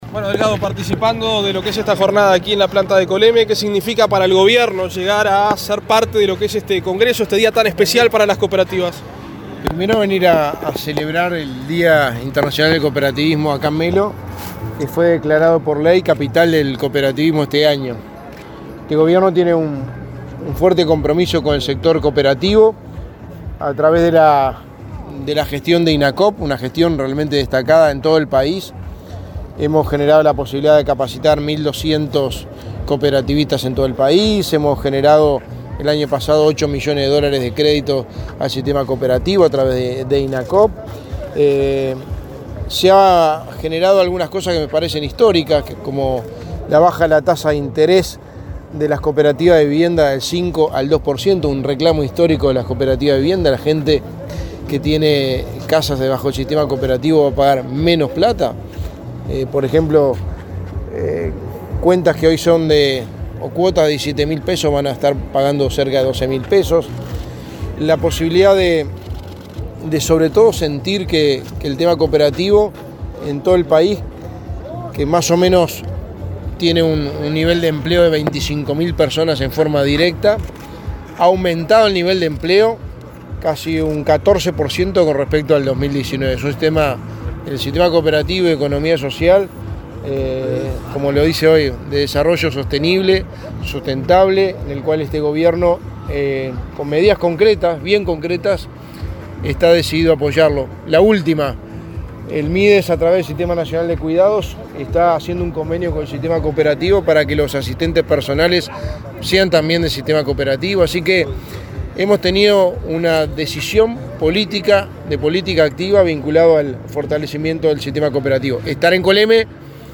Declaraciones del secretario de Presidencia, Álvaro Delgado, en Coleme
El secretario de la Presidencia de la República,Álvaro Delgado, dialogó con medios informativos en Melo, antes de participar de la ceremonia por el